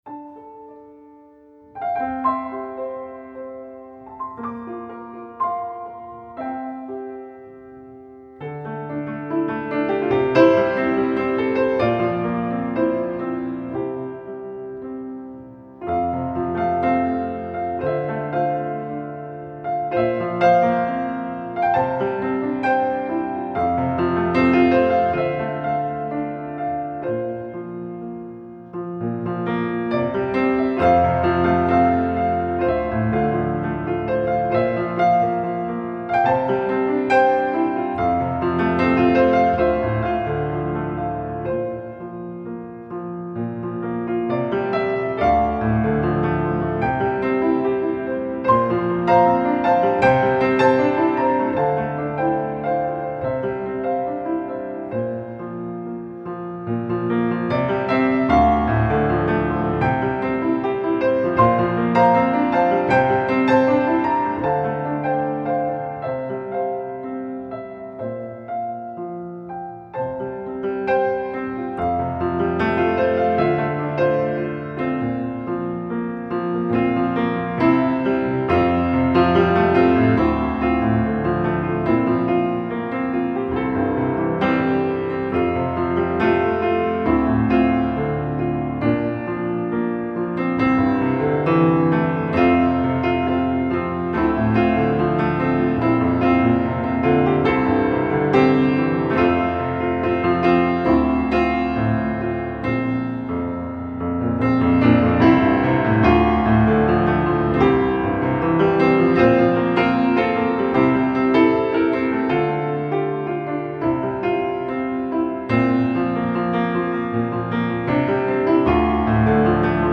pop/instrumental crossover